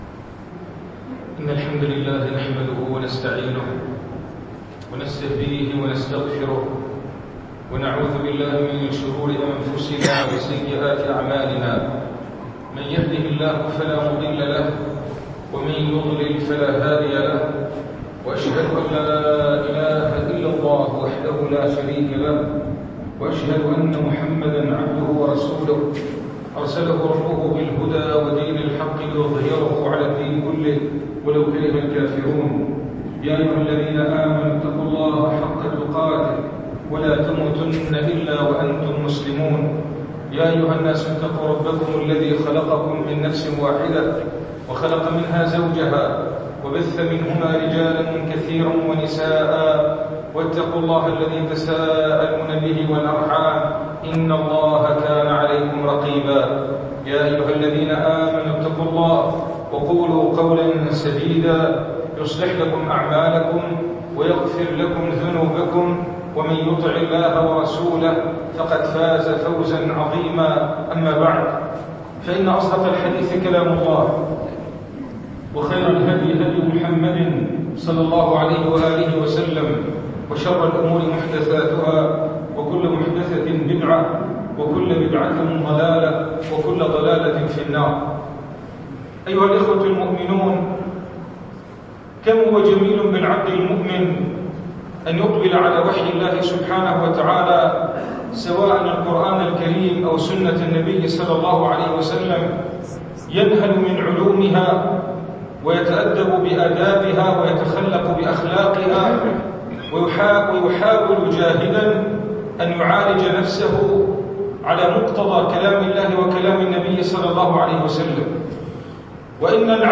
[منبر الجمعة]